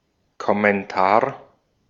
Ääntäminen
Ääntäminen France: IPA: [kɔ.mɑ̃.tɛʁ] Haettu sana löytyi näillä lähdekielillä: ranska Käännös Ääninäyte Substantiivit 1.